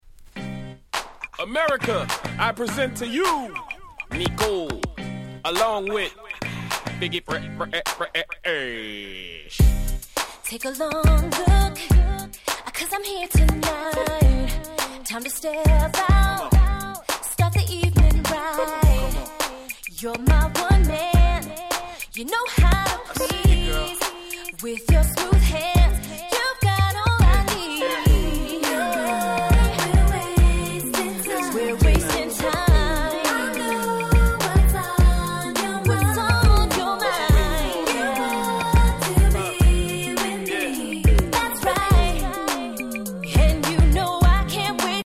ダンクラ調で展開が非常に気持ちの良い
※試聴ファイルはWhite盤から録音したものです。